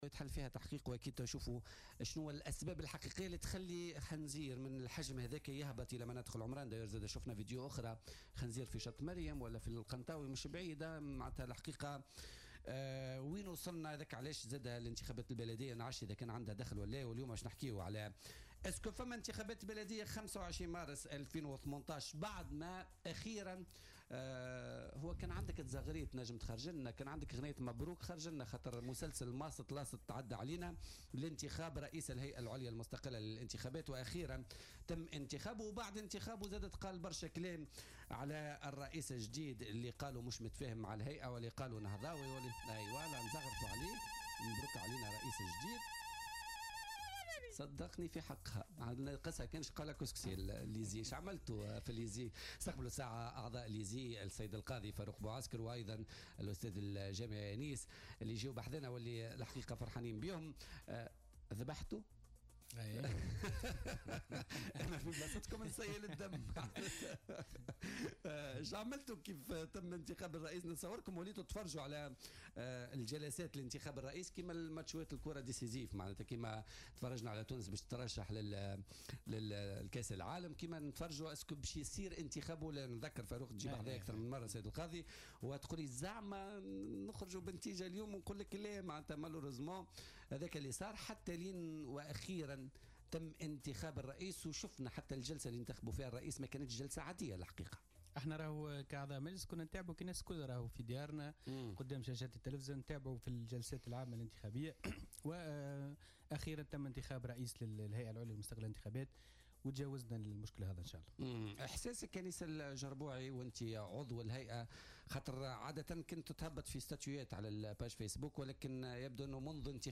أكد عضو الهيئة العليا المستقلة للانتخابات أنيس الجربوعي ضيف بولتيكا اليوم الإثنين 20 نوفمبر 2017 أن الانتهاء من انتخاب رئيس الهيئة أنهى أزمتها ومن شأنه أن يفتح لها المجال لتعمل على مواجهة الاستحقاقات الانتخابية الهامة .